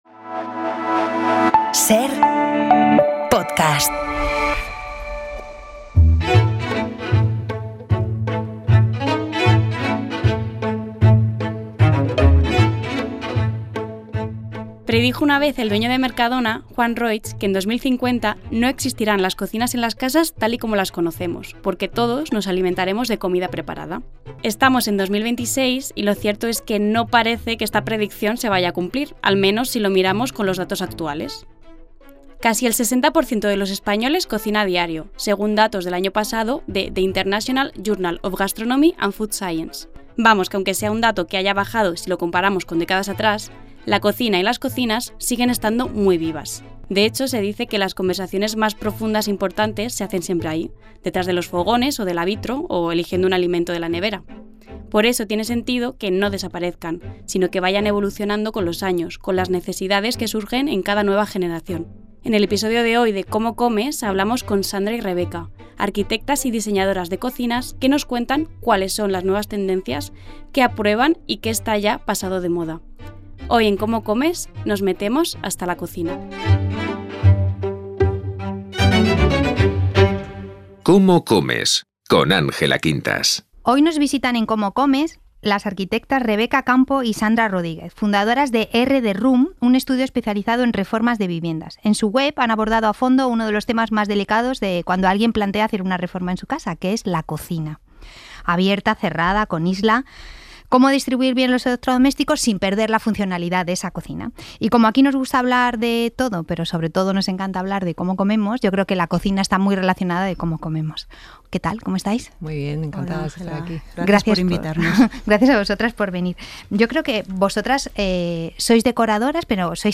Cómo comen... las diseñadoras de cocinas 36:00 SER Podcast En este episodio hablamos de cómo organizar la cocina, qué está de moda y cuáles son las tendencias con dos arquitectas especializadas en cocinas.